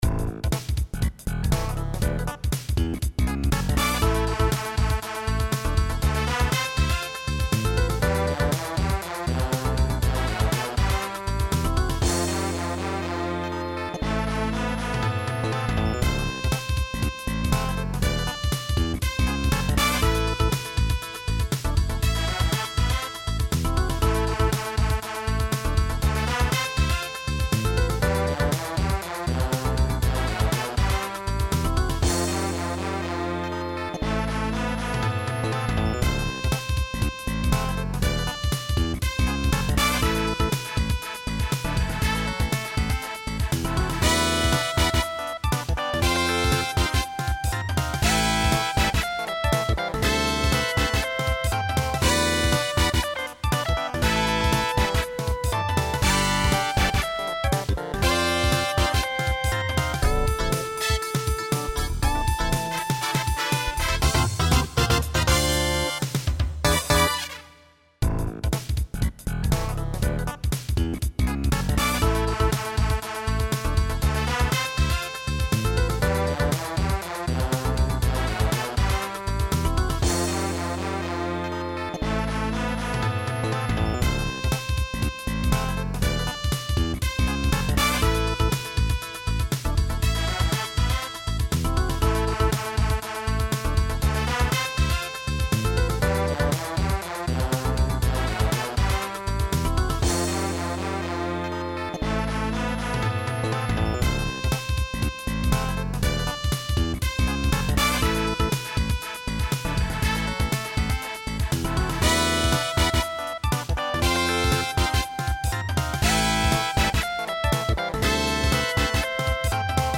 オリジナル(インスト)